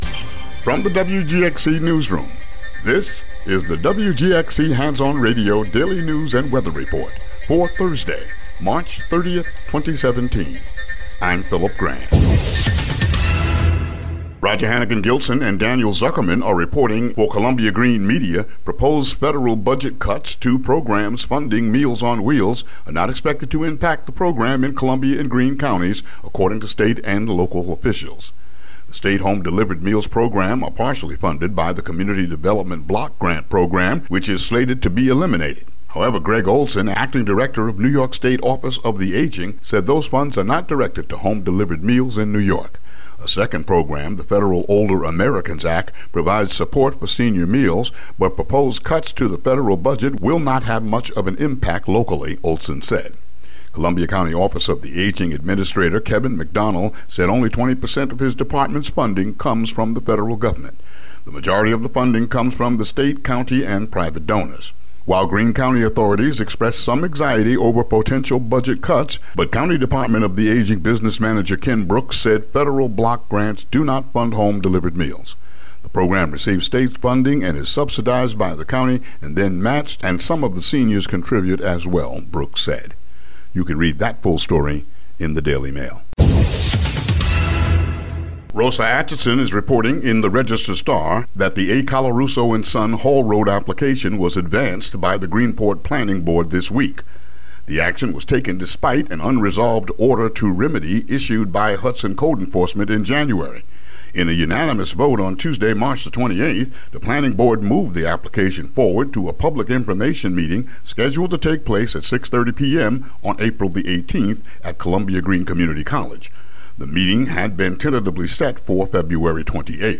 WGXC Local News